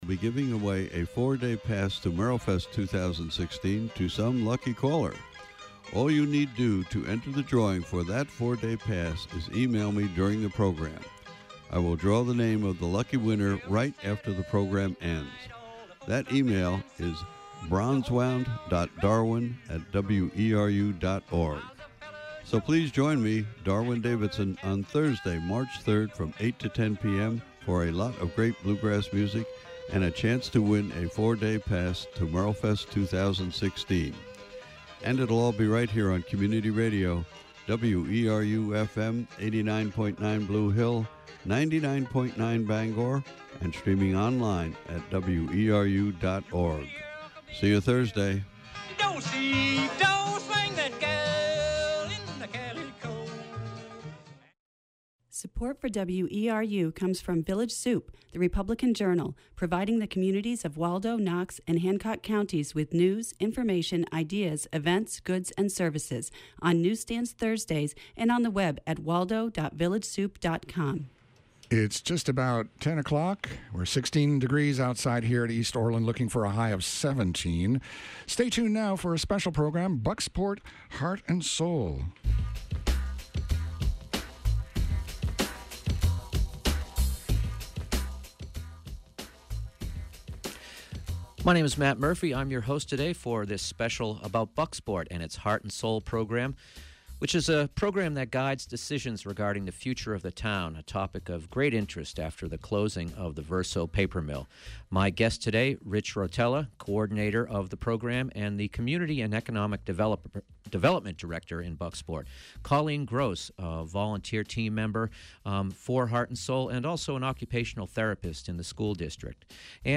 The Democratic-controlled Maine House of Representatives voted yesterday to kill a proposed order calling for an investigation into whether any of a list of actions by Governor LePage would constitute grounds for impeachment. Today we bring you to the House floor for the first hour of their debate.